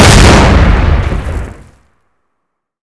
explode5.wav